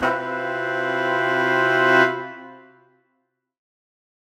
Index of /musicradar/undercover-samples/Horn Swells/C
UC_HornSwell_Cmaj7b5.wav